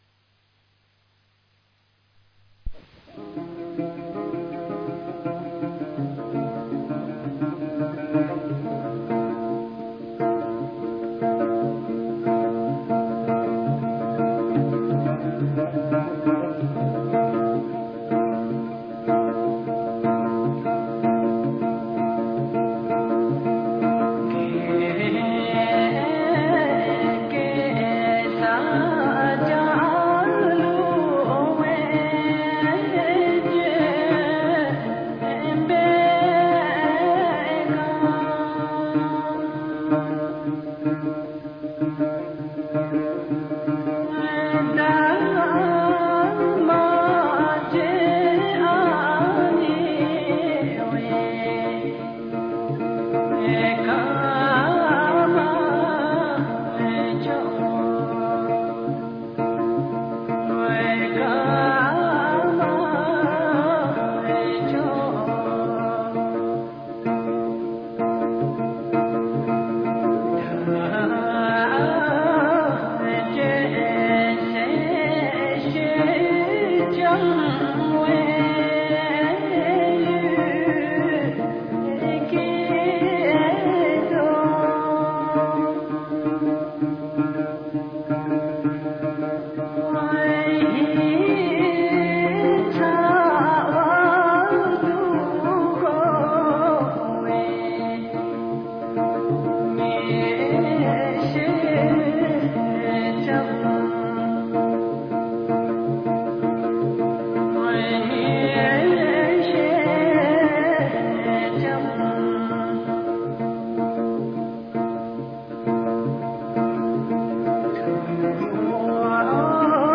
ブータンの音楽
「Gyop Gyeser Ganglu」という曲で、ゲッサルと言う戦士が、戦いに行く際、残った家族に向けて思いを述べる歌らしい。伴奏は「ジャムゲ」と呼ばれる弦楽器で、三味線にそっくりな形をしている。
節回しが日本民謡とそっくりなのである。江刺追分を思わせるようなその旋律が気に入り、日本に帰ってからも何度も聴いた。 　この曲は日本民謡の田舎節（ドレミソラド）の音階だが、その他にも沖縄的な音階の音楽や、インドの影響を受けたと思われる音階の音楽もあって実に面白い。
bhutanmusic.mp3